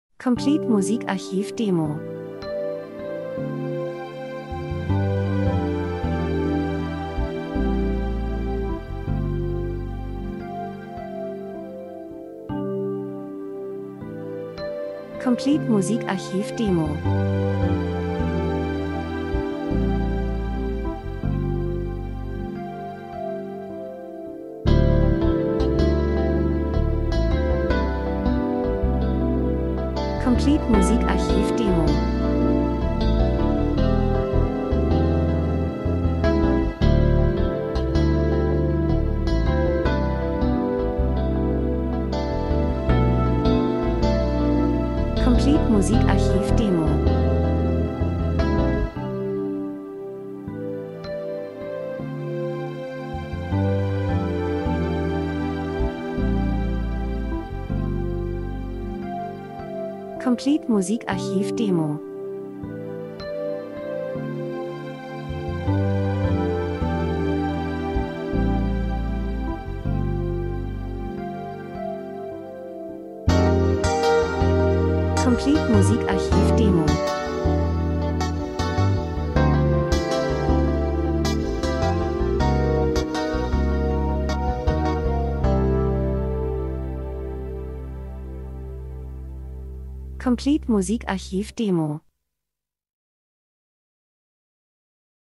Easy Listening Stimmungsvoll, Nachdenklich